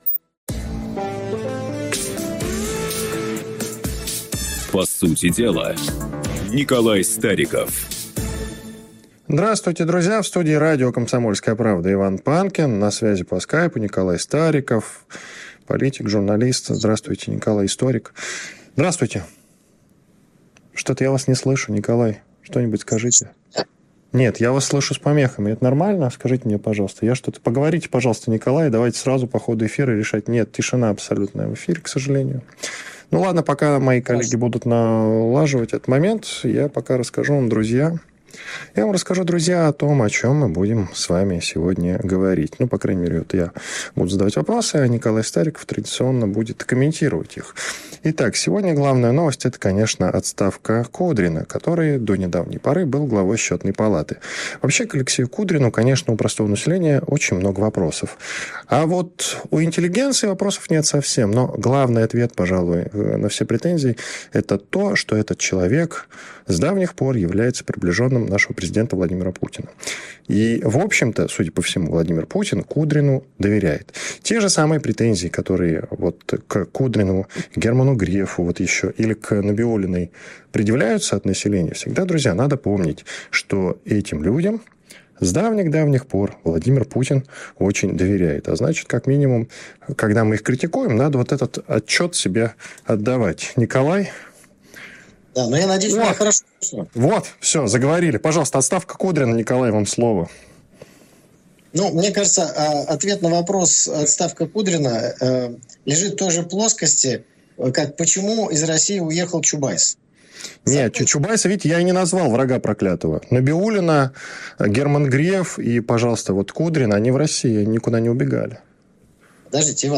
В очередном выпуске программы «По сути дела», что выходит каждый вторник в 19:00 на радио «Комсомольская Правда» (97,2FM), обсудили ряд важных вопросов из политической ленты новостей.